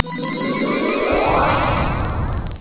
teleport.wav